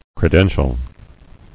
[cre·den·tial]